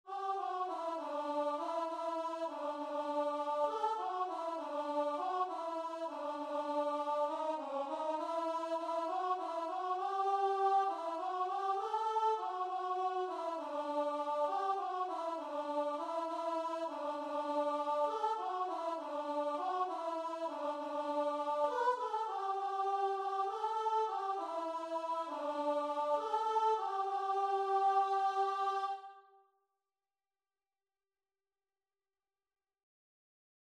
Christian
6/8 (View more 6/8 Music)
Classical (View more Classical Guitar and Vocal Music)